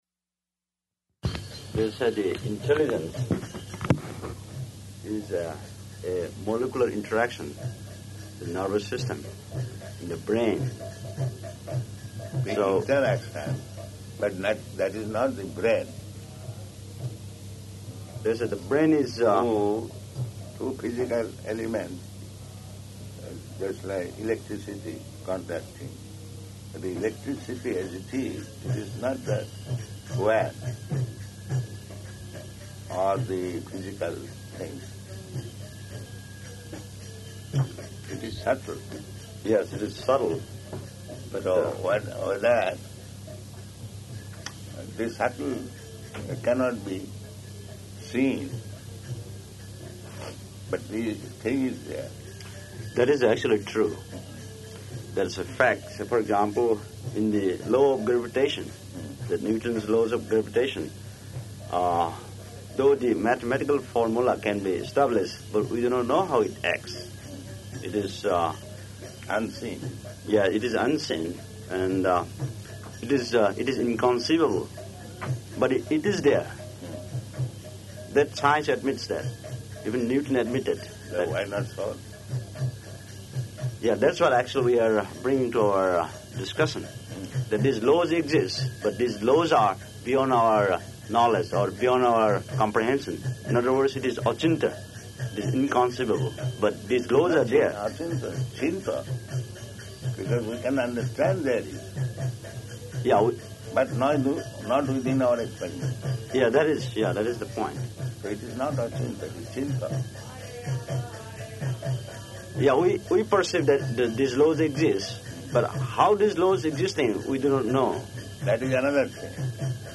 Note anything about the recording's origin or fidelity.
Location: Bhubaneswar